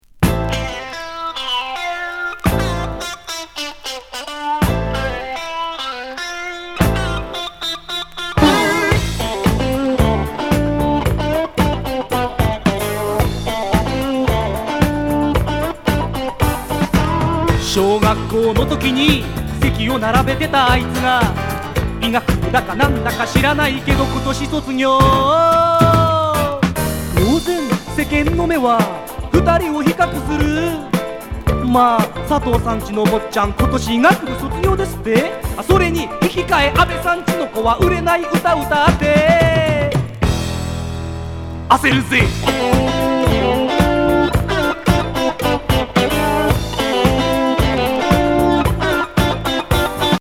ファンキー・フォーク・グルーヴ＋コミカルVo！